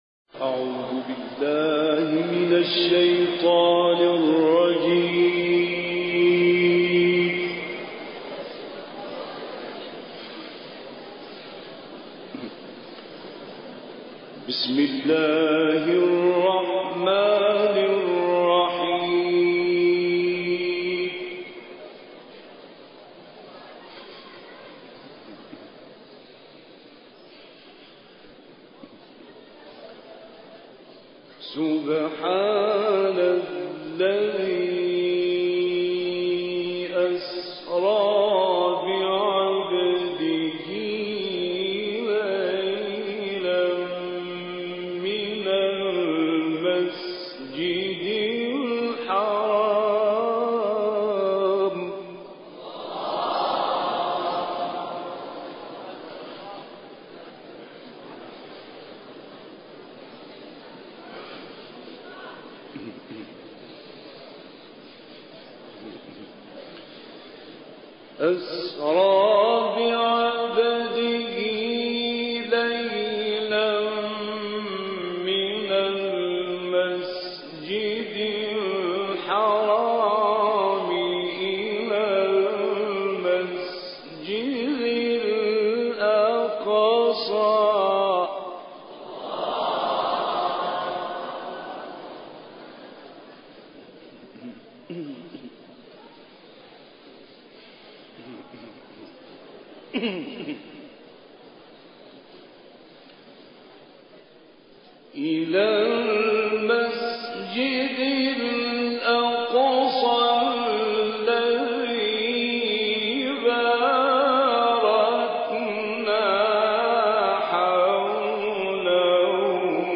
تلاوت استاد «غلوش» در قم + دانلود
به گزارش خبرگزاری بین المللی قرآن (ایکنا) ، تلاوت شنیدنی مرحوم استاد راغب مصطفی غلوش از سوره اسرا آیات ١ تا ١٥ و سوره بلد آیات ١ تا ١٢ که در مسجد اعظم قم اجرا شده، ارائه می‌شود.